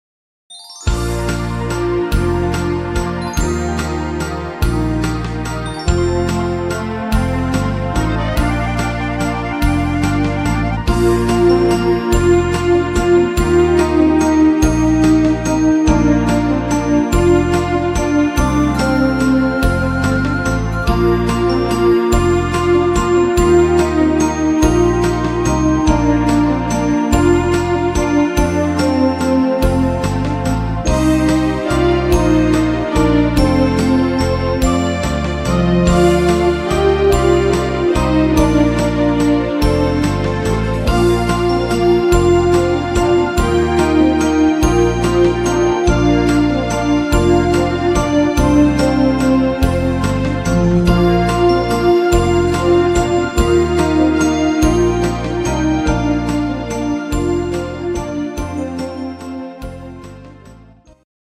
Schunkel-Walzer